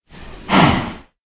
sounds / mob / cow / say4.ogg